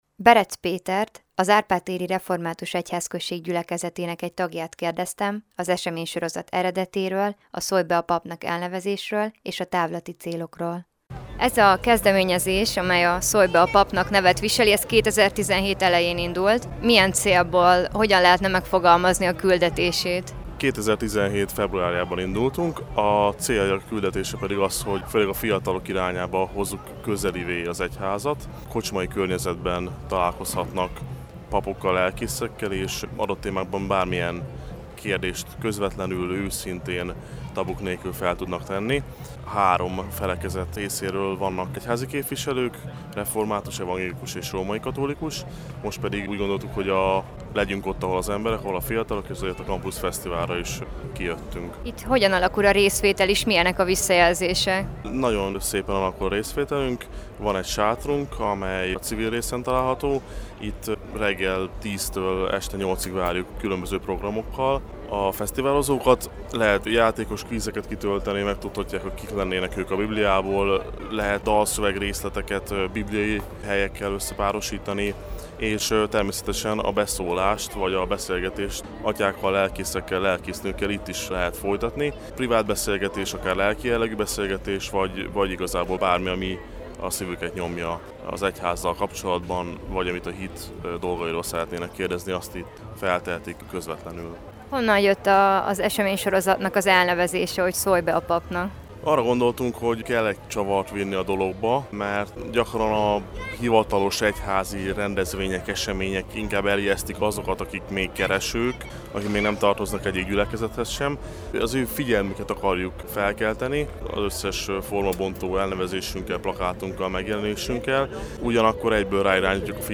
Forrás: Európa Rádió